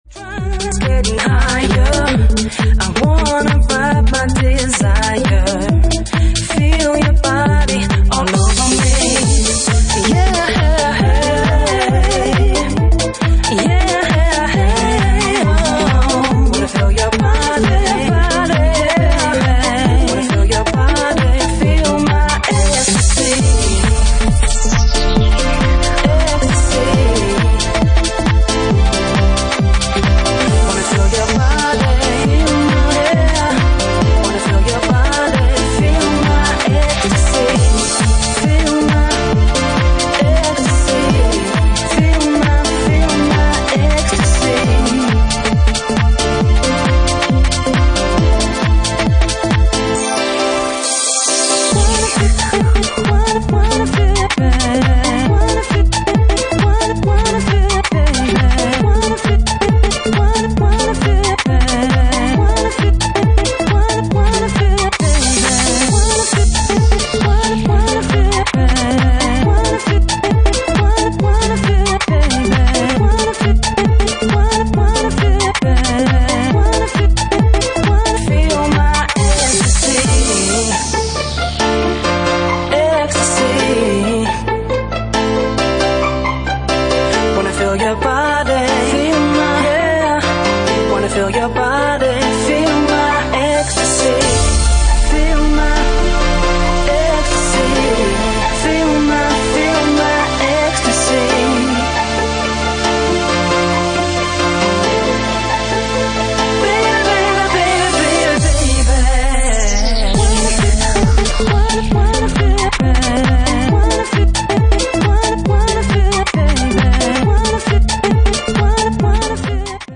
Genre:Bassline House
Bassline House at 136 bpm